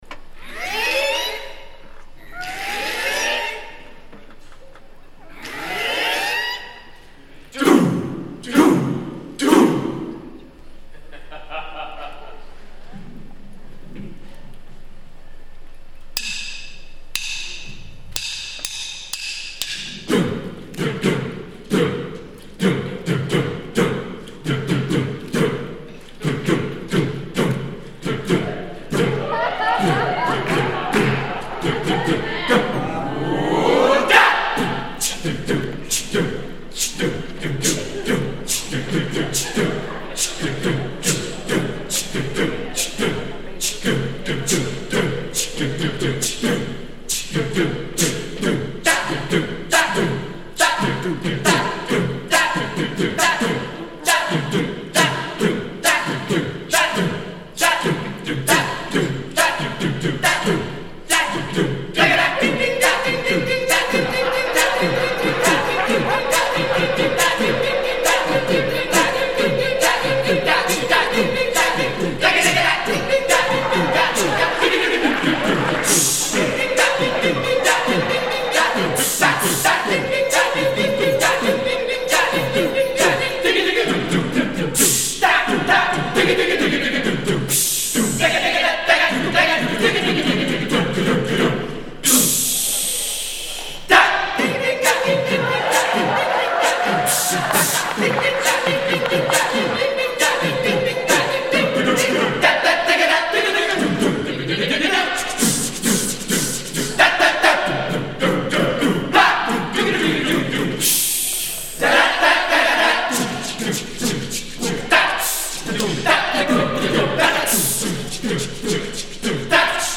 Voicing: 5-17 Percussion